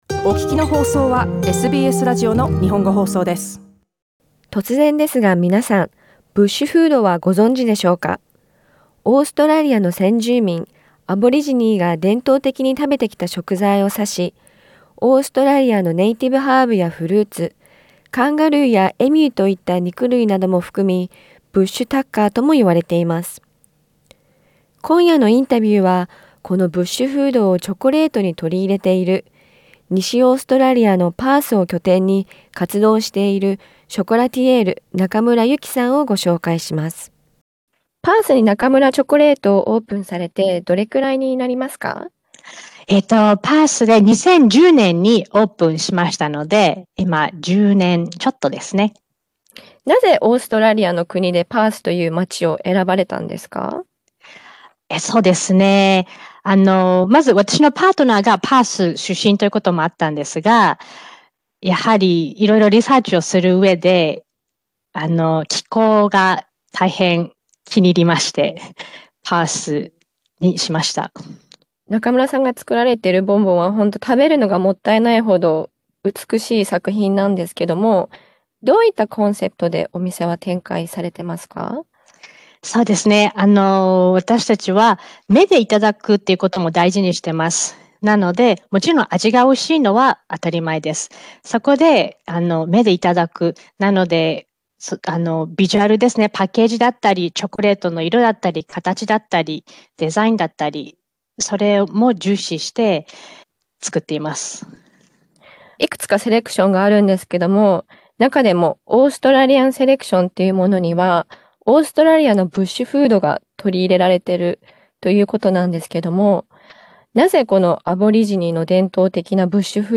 インタビューでは気温の暑いオーストラリアでのチョコレートの保管方法なども伺いました。